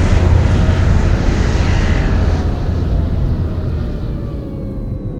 landing.ogg